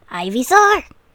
IVYSAUR.wav